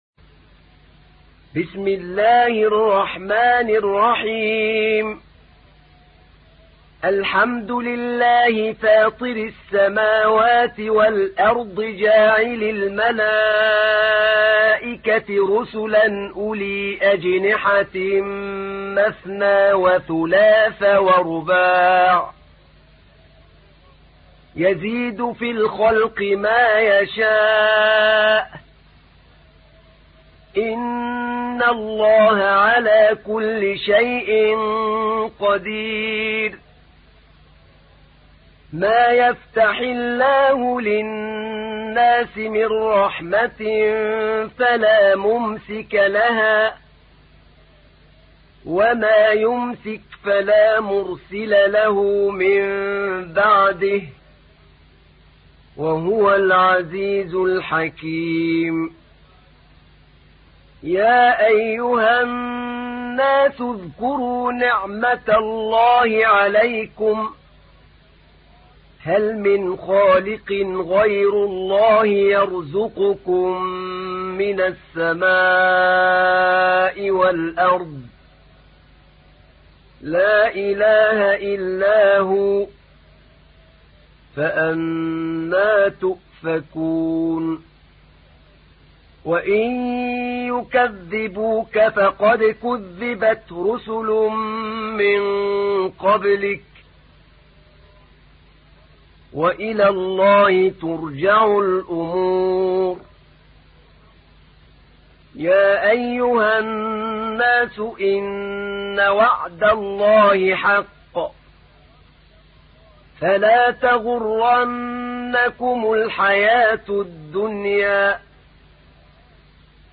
تحميل : 35. سورة فاطر / القارئ أحمد نعينع / القرآن الكريم / موقع يا حسين